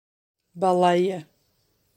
SpråkUttale